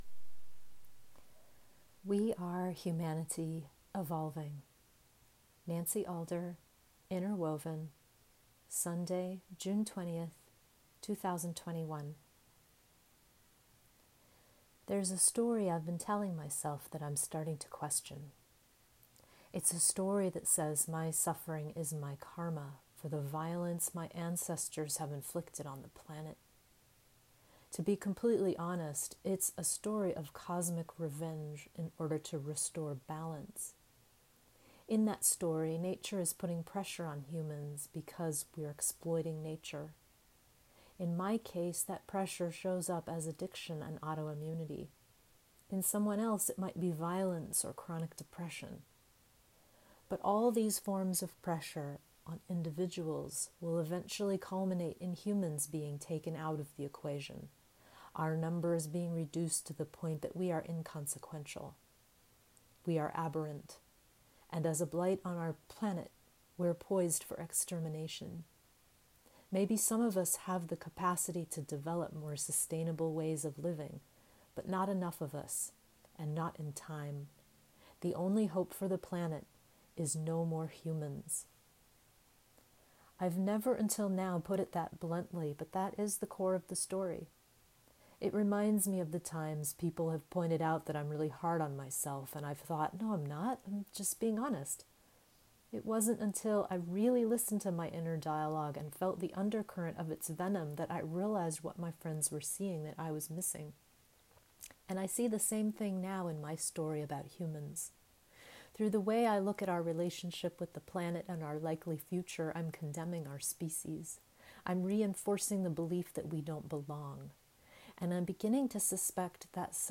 Enjoy this 10-minute read or have me read it to you via the audio file at the top of the webpage!